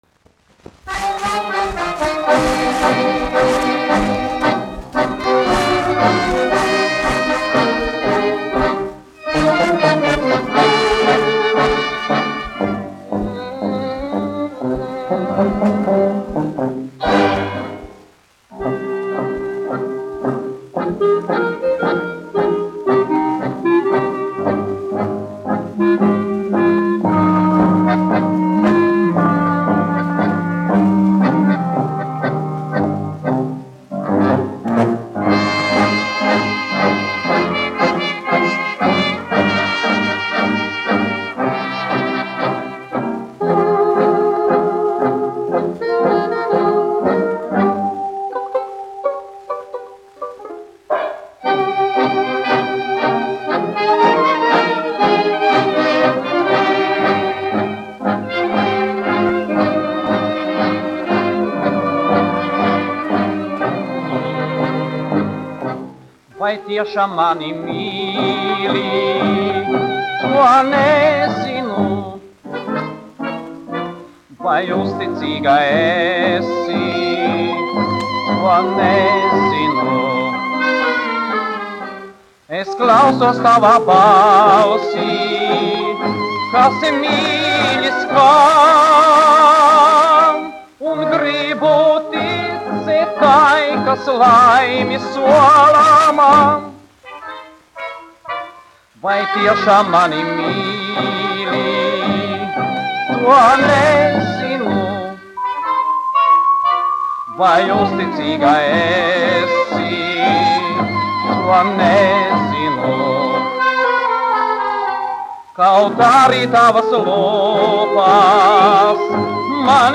1 skpl. : analogs, 78 apgr/min, mono ; 25 cm
Kinomūzika
Skaņuplate
Latvijas vēsturiskie šellaka skaņuplašu ieraksti (Kolekcija)